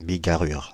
Ääntäminen
Ääntäminen France (Île-de-France): IPA: /bi.ɡa.ʁyʁ/ Haettu sana löytyi näillä lähdekielillä: ranska Käännöksiä ei löytynyt valitulle kohdekielelle.